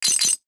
NOTIFICATION_Glass_12_mono.wav